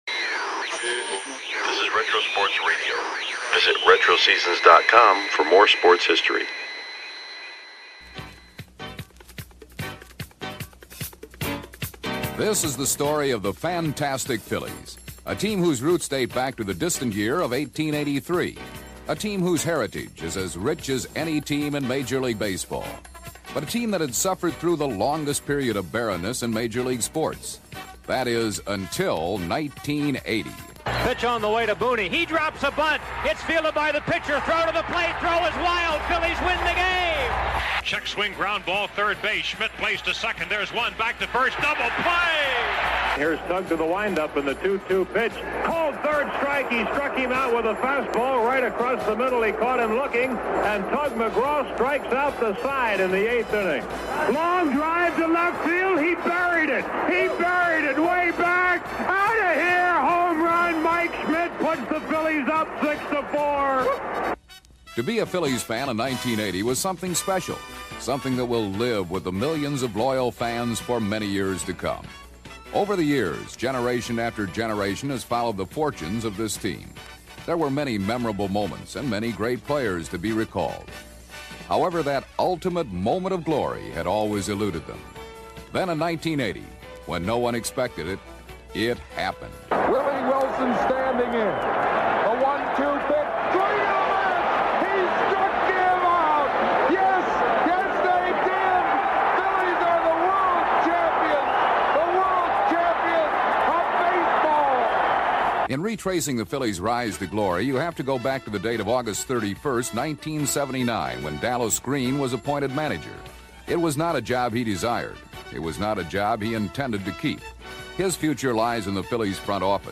1977-Nov-25 • PHI/GSW • Philadelphia 76ers vs Golden State Warriors - NBA Radio Broadcast – Retro Sports Radio: Classic Games from History – Podcast